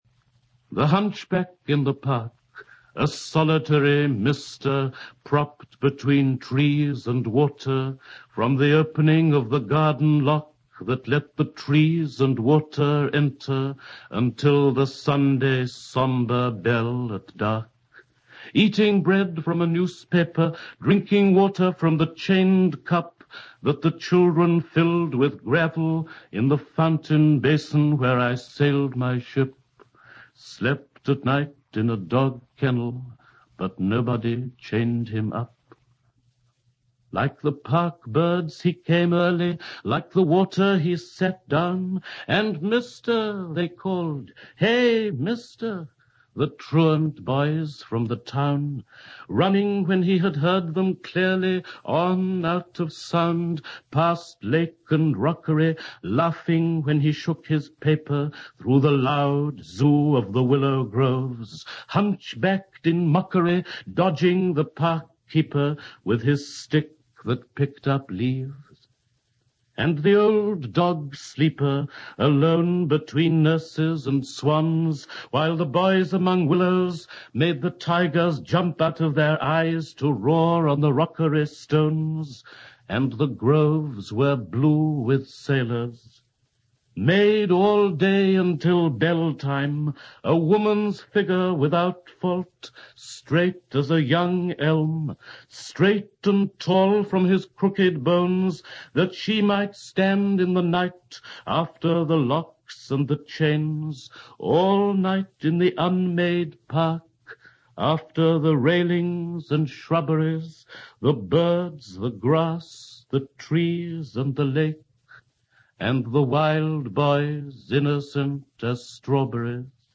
The poem below is read by the poet himself.